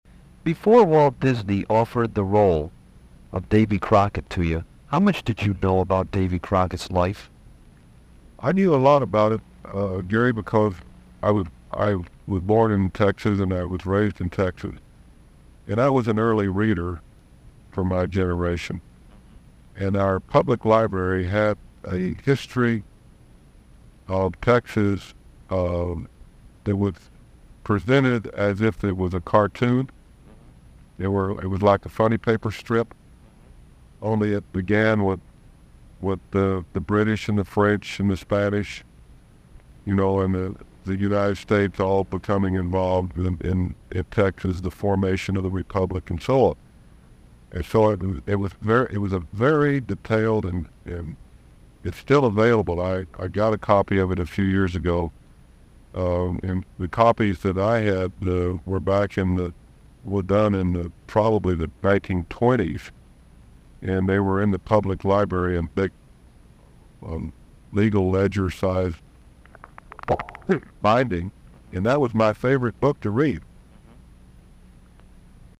Fess Parker Interview Davy Crockett